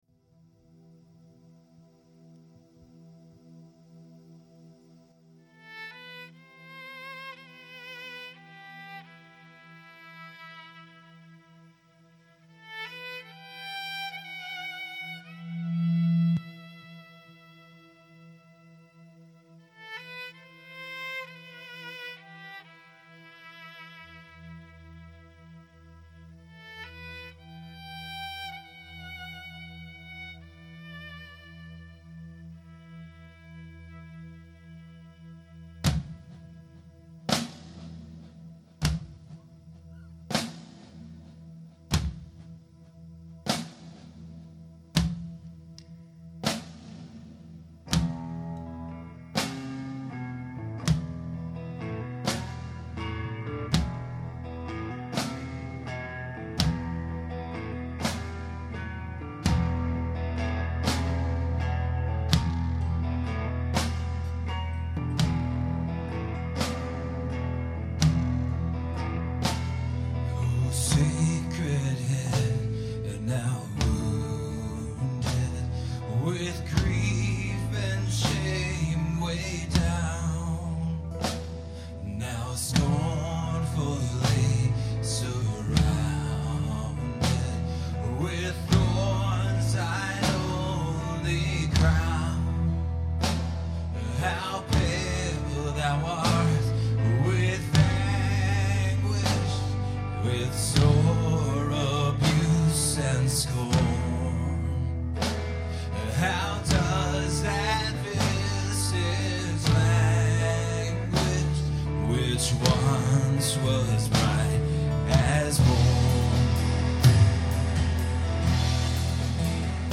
Performed live on Good Friday at Terra Nova - Troy on 3/21/08.